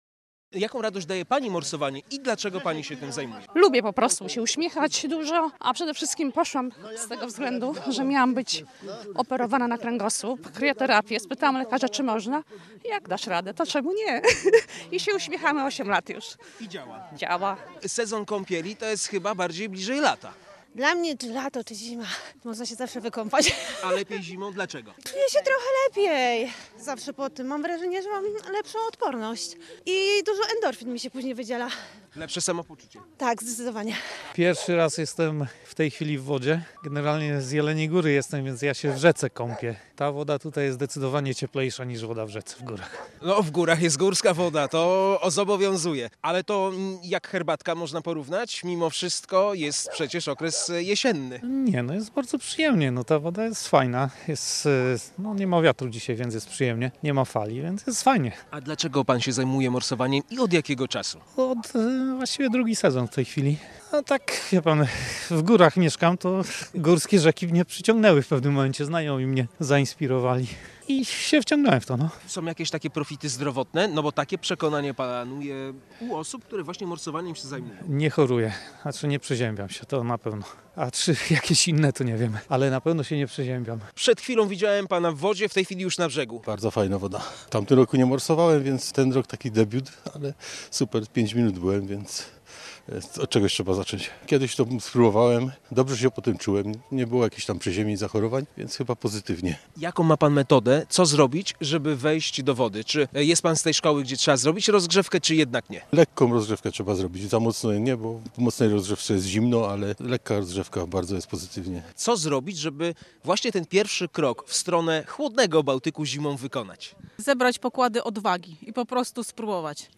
W oficjalnym rozpoczęciu sezonu bałtyckich morsów na usteckiej plaży udział wzięło kilkadziesiąt osób, dla których lodowata kąpiel jest najlepsza formą relaksu.